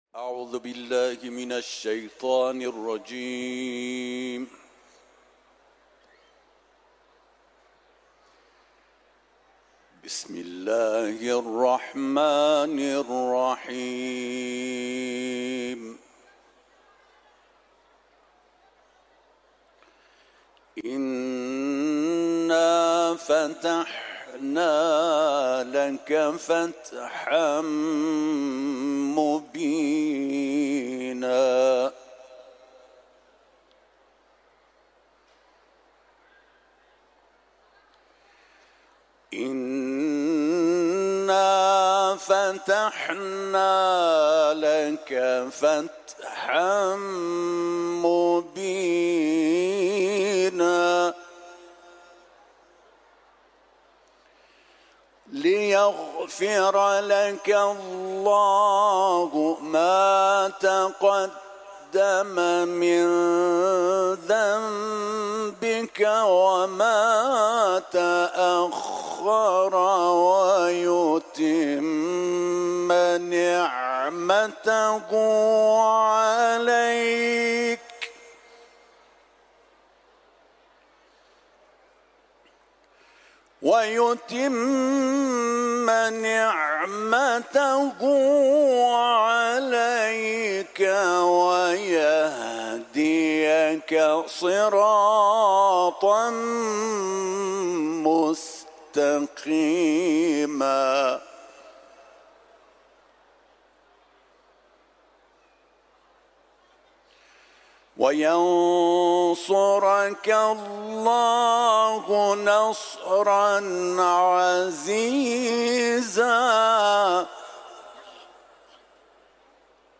تلاوت